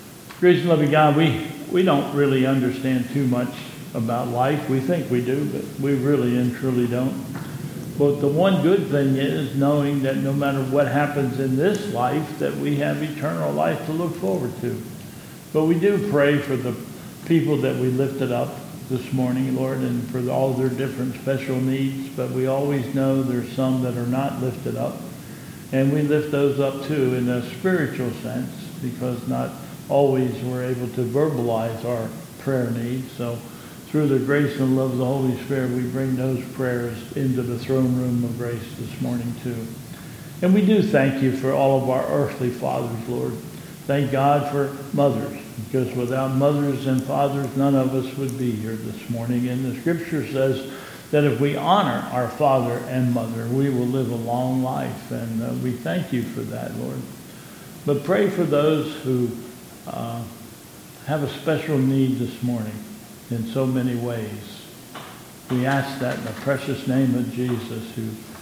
Prayer Concerns